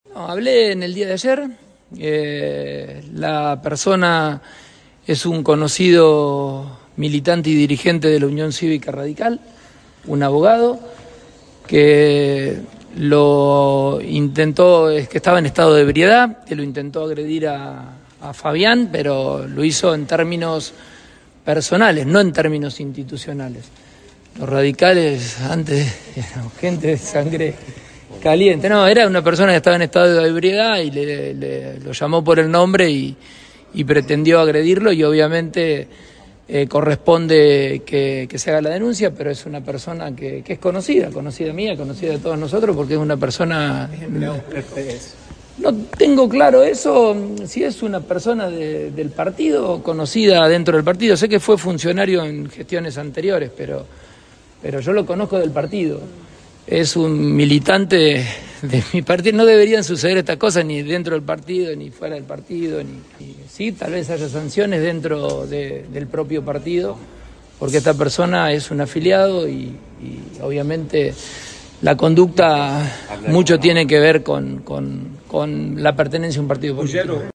Durante la recorrida las obras de puesta en valor del edificio de Escuela Normal Superior de Comercio -4 de Enero 2830-, Pullaro relató que era una persona que estaba en estado de ebriedad, que lo llamó por el nombre y pretendió agredirlo, por lo que consideró correspondeidente realizar la denuncia.